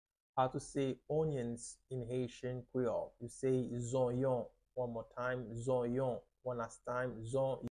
How to say "Onions" in Haitian Creole - "Zonyon" Pronunciation by a native Haitian Teacher
“Zonyon” Pronunciation in Haitian Creole by a native Haitian can be heard in the audio here or in the video below:
How-to-say-Onions-in-Haitian-Creole-Zonyon-Pronunciation-by-a-native-Haitian-Teacher.mp3